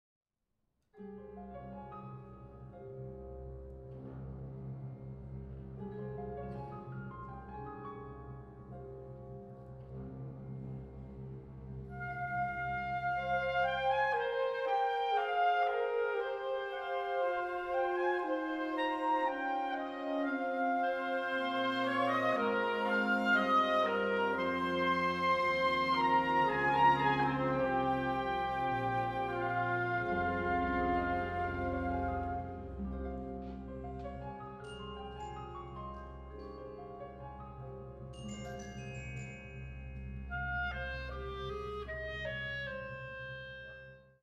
Catégorie Harmonie/Fanfare/Brass-band
Sous-catégorie Musique originale contemporaine
Instrumentation Ha (orchestre d'harmonie)